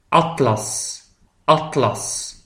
PRONONCIATION :